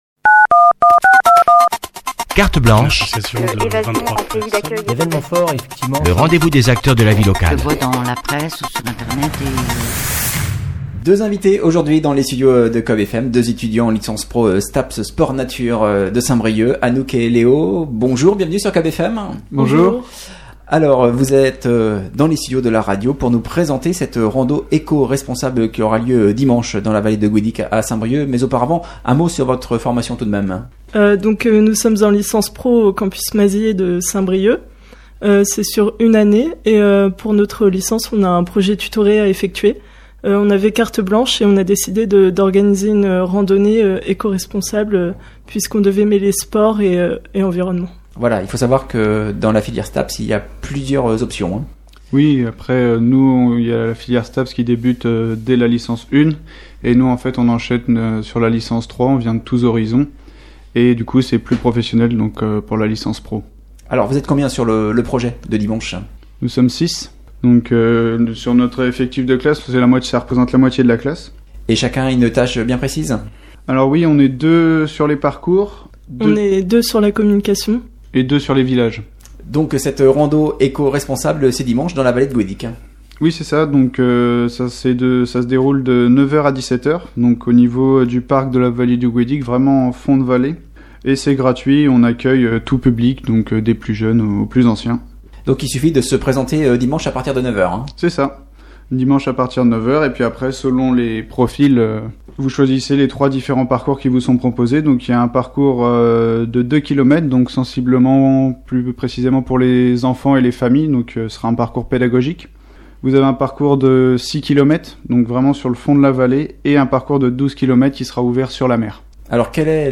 Invités du jour sur COB’FM